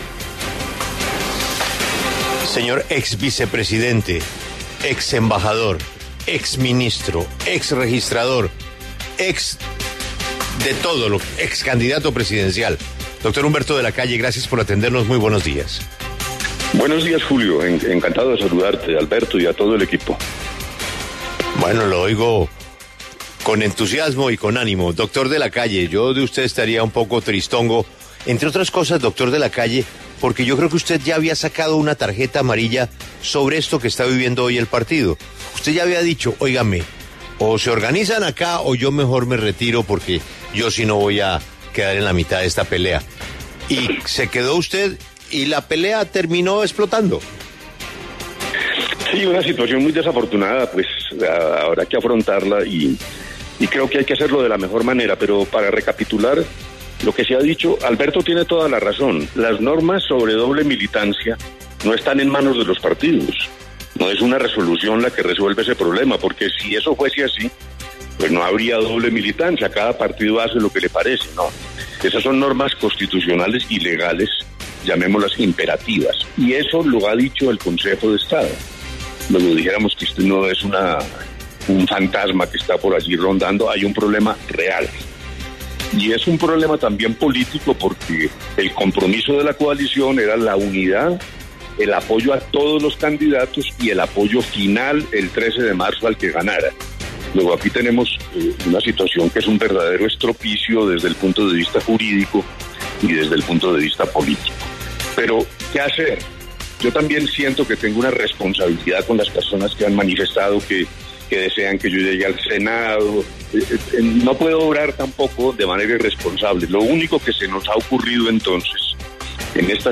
En diálogo con La W, el candidato al Senado, Humberto de la Calle, reconoció que el retiro de Ingrid Betancourt de la Coalición Centro Esperanza lo deja en una situación “muy desafortunada” que debe afrontar.